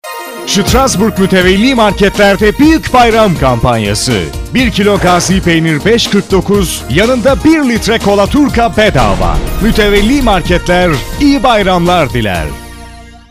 male voice
Kein Dialekt
Sprechprobe: eLearning (Muttersprache):